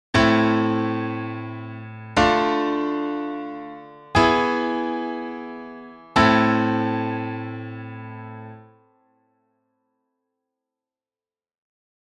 In A minor, this raises the G to a G♯:
i-iv-V-i chord progression in A minor harmonic
i-iv-V-i Progression in A minor